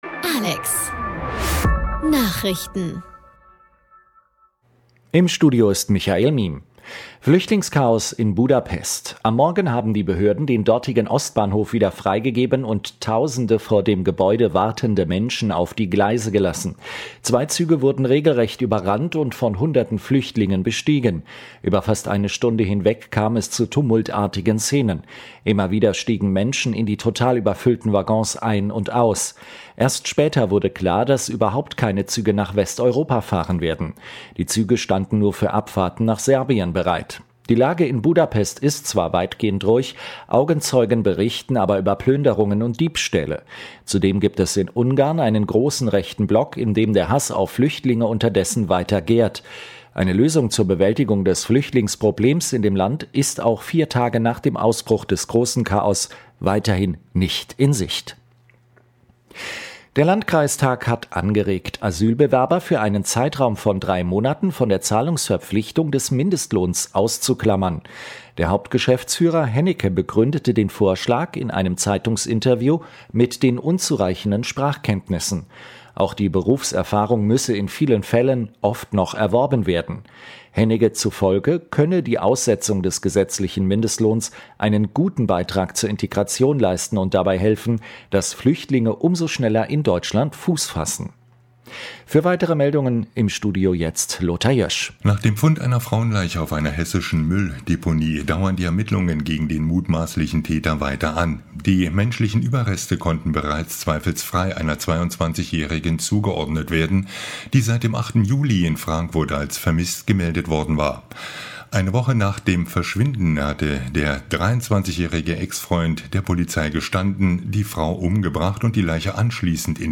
Der Krieg gegen Erwerbslose – Hartz IV Leistungskürzungen verfassungswidrig? Interview